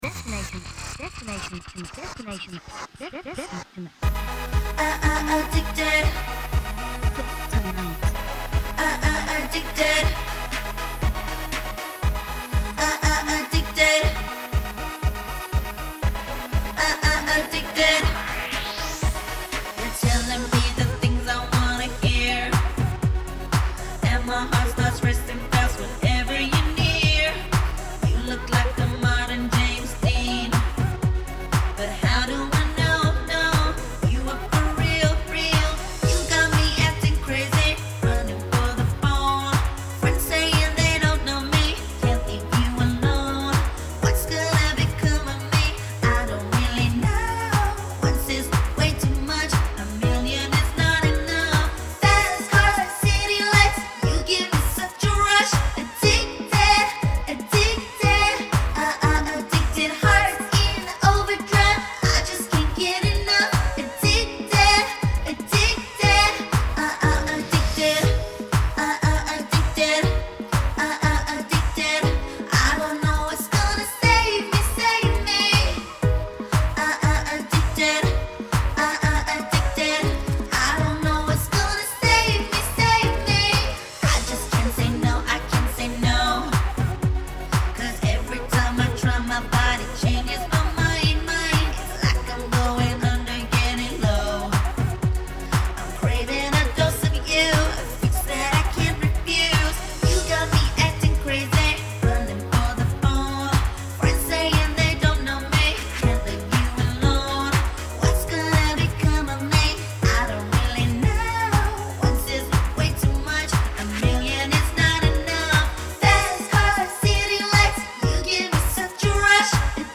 Midtempo pop/dance Fm vx/dance